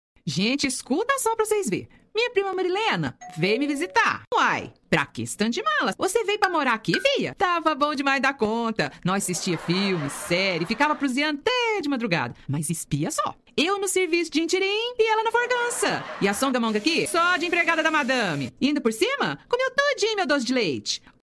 mineirês: